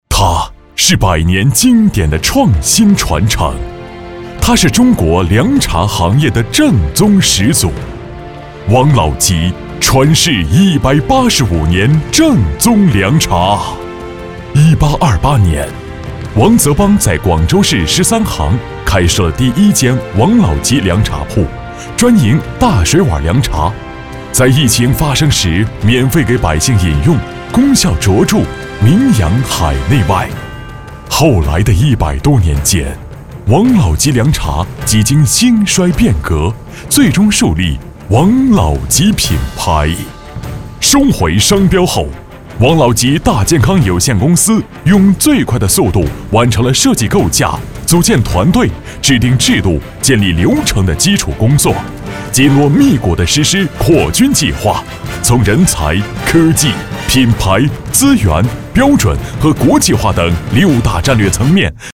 男声配音